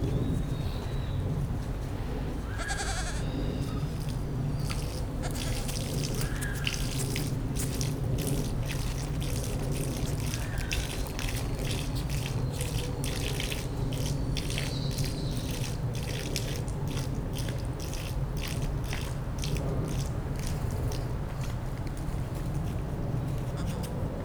lama